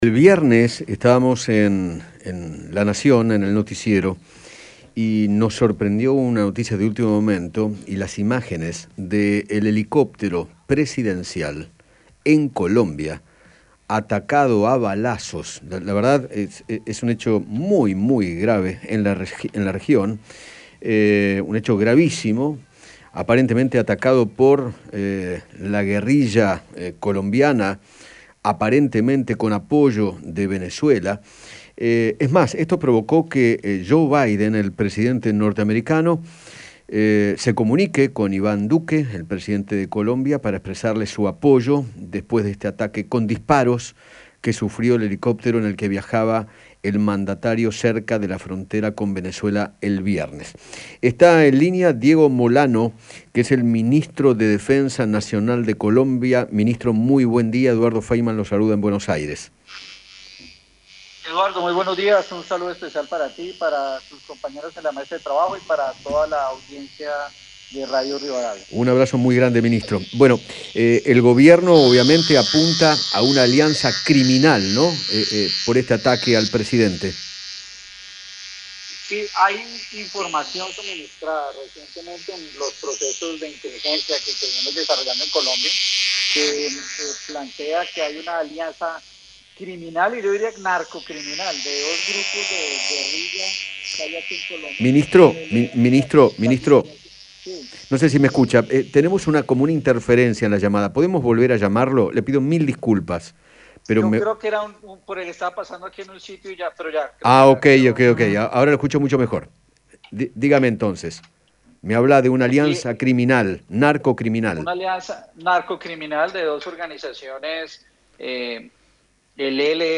Diego Molano, ministro de Defensa de Colombia, dialogó con Eduardo Feinmann sobre el ataque a balazos al helicóptero en el que viajaba el presidente colombiano, Iván Duque, y expresó que  “en los últimos dos meses se inspiró una espiral de violencia en el país que busca afectar a la institucionalidad”.